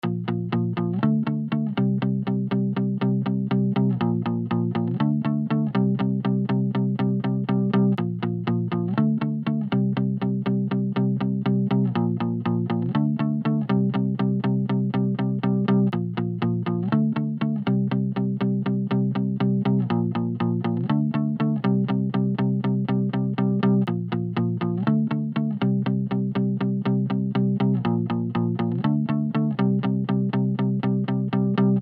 guitar-sound.mp3